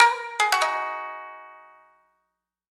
На этой странице собраны звуки сямисэна — уникального трёхструнного инструмента, популярного в японской музыке.